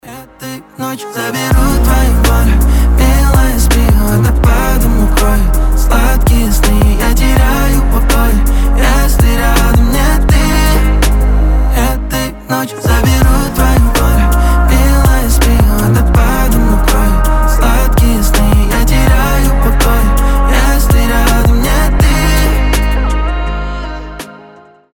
лирика
красивый мужской голос
RnB